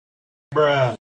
bruh-sound-effect-320.mp3